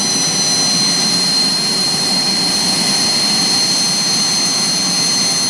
starter-loop.wav